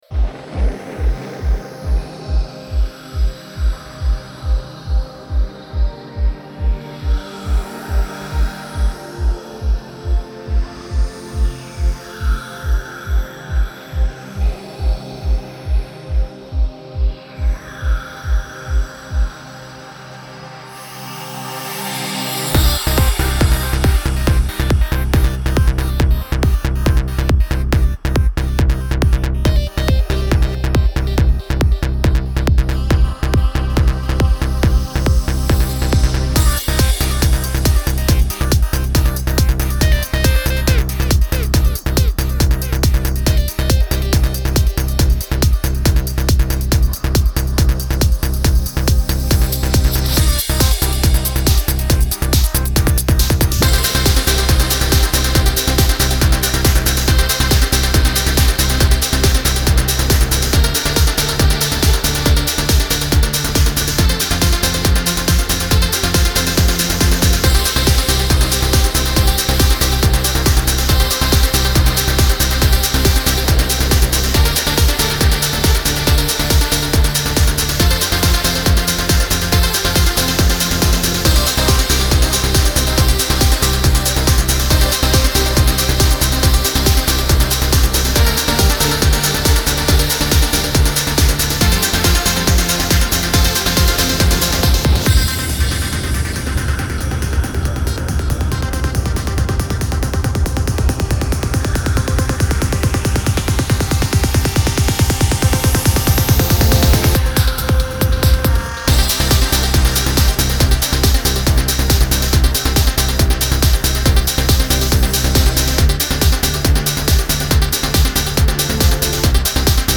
Genre : Électronique, Techno